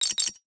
NOTIFICATION_Metal_11_mono.wav